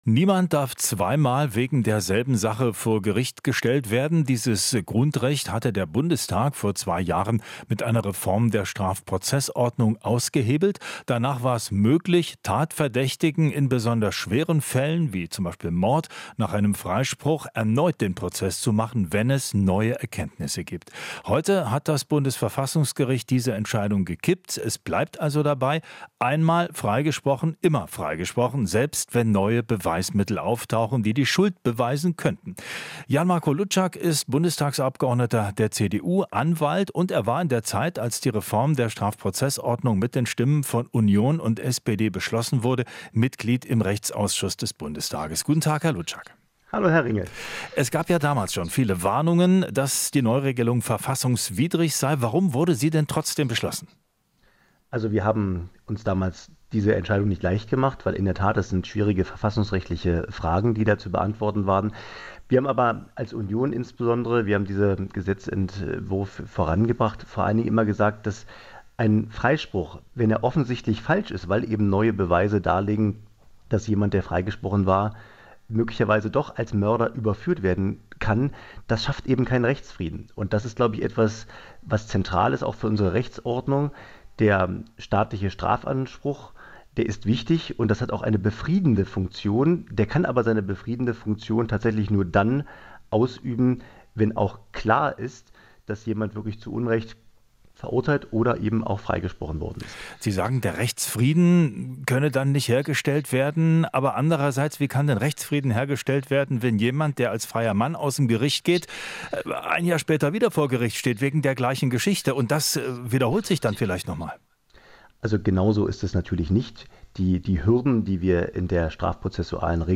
Interview - CDU-Rechtsexperte: Zweites Urteil kann Rechtsfrieden schaffen